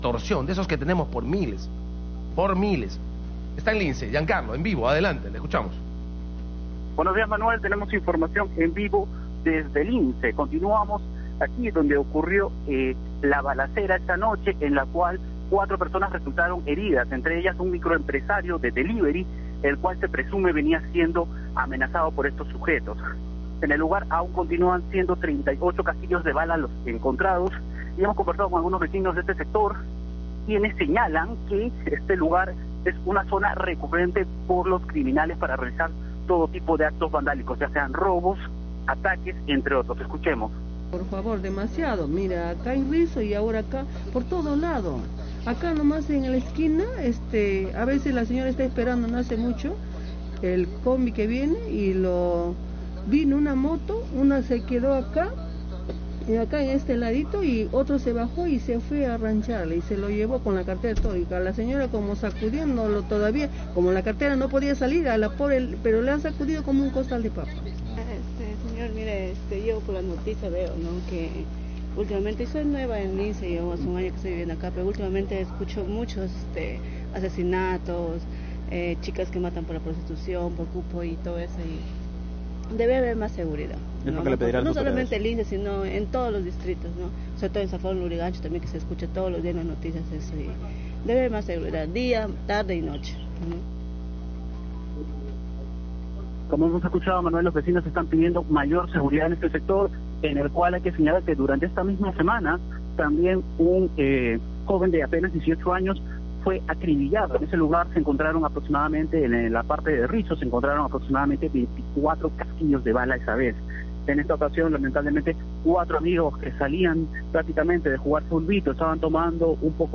Desde Lince, la reportera del programa, informó que los vecinos del jirón Cápac Yupanqui piden mayor seguridad tras una balacera ocurrida el día de ayer por la noche.